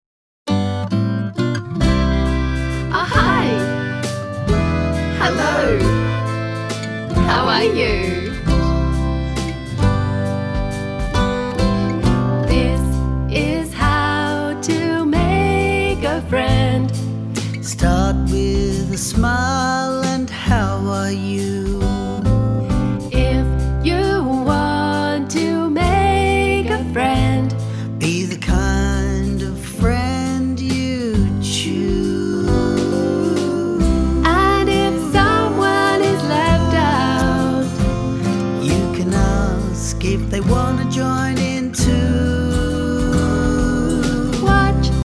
1. Mp3 vocal track;